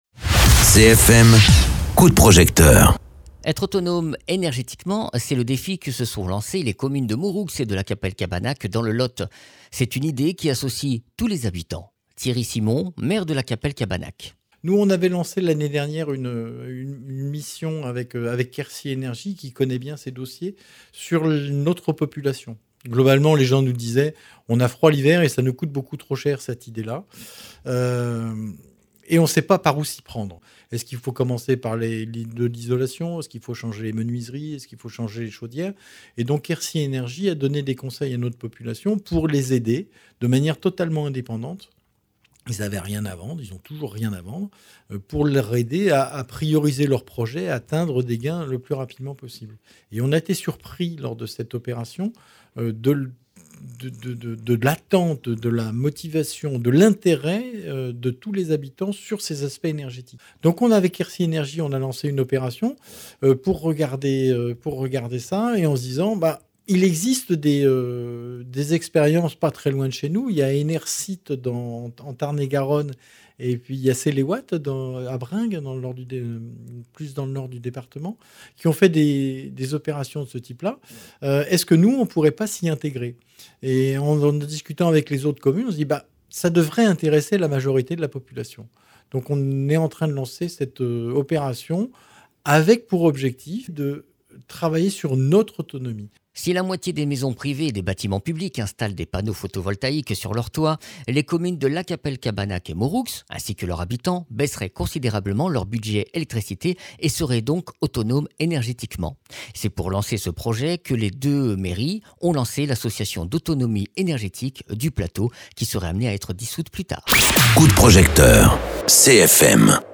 Interviews
Invité(s) : Thierry Simon, maire de Lacapelle-Cabanac